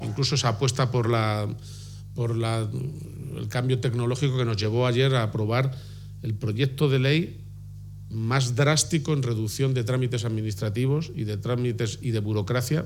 >> García-Page anuncia la medida durante la inauguración del centro de datos de Fortinet en Torija, Guadalajara